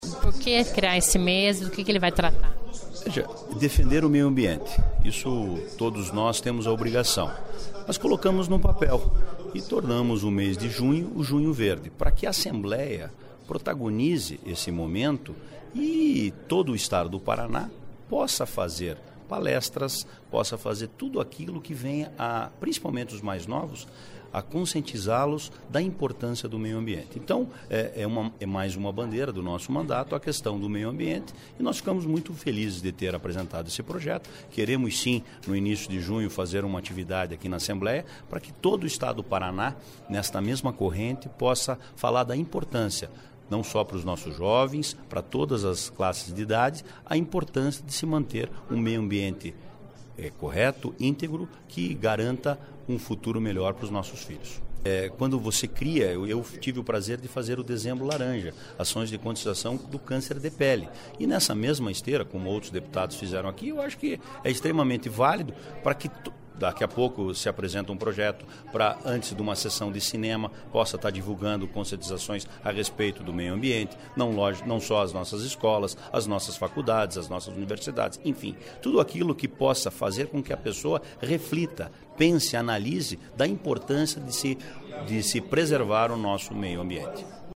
Ouça entrevista do autor da proposta.